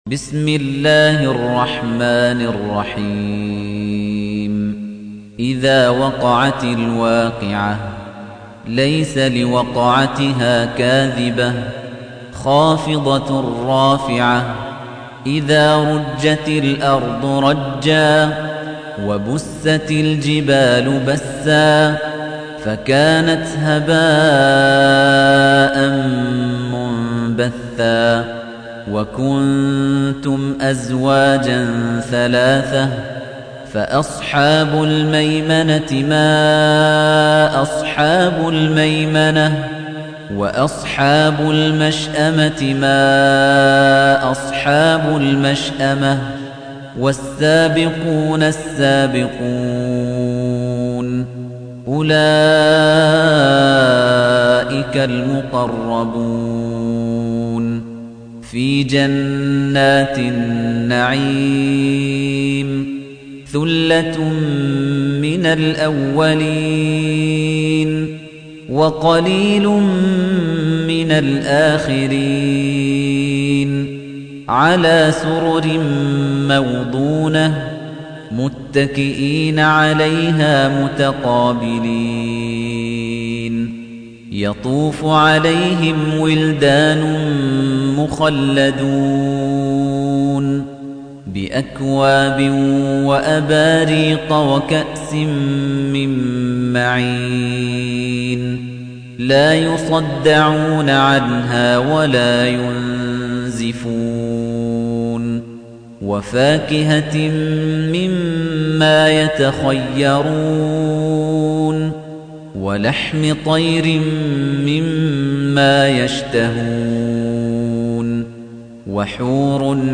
تحميل : 56. سورة الواقعة / القارئ خليفة الطنيجي / القرآن الكريم / موقع يا حسين